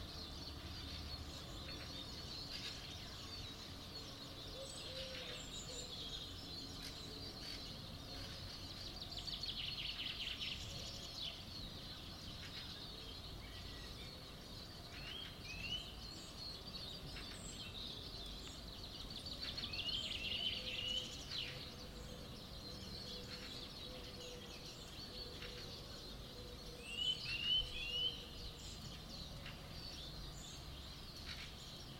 高级音响效果 自然和鸟类 1
描述：FX自然和鸟类的声景。用H2next录制的。